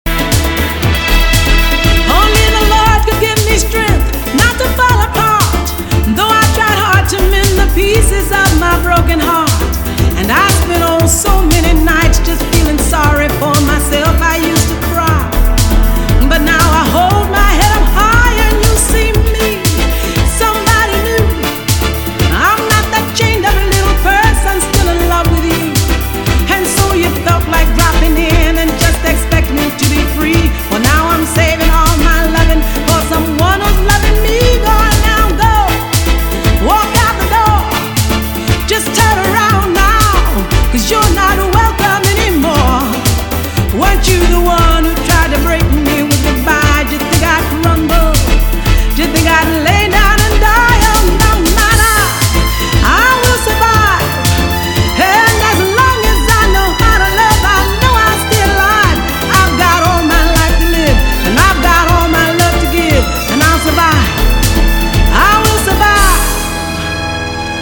• Качество: 256, Stereo
ритмичные
ретро
шлягер